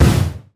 Frisbee Explode Sound Effect
Download a high-quality frisbee explode sound effect.
frisbee-explode.mp3